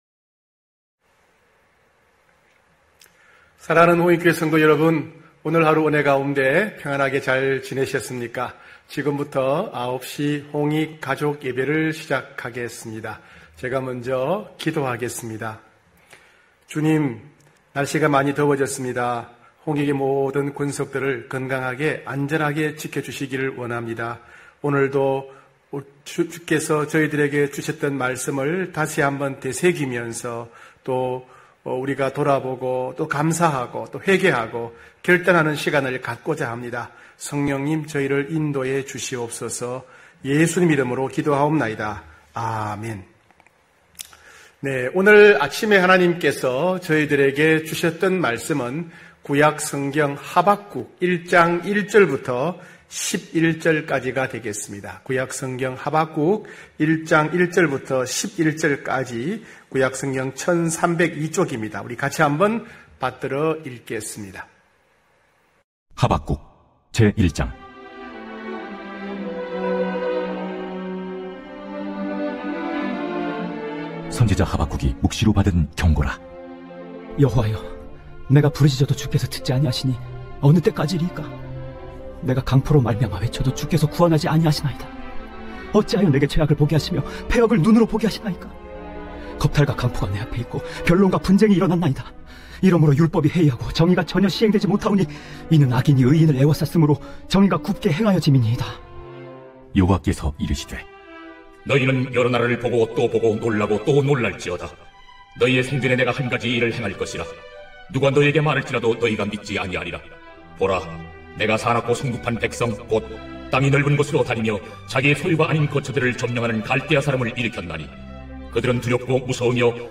9시홍익가족예배(5월27일).mp3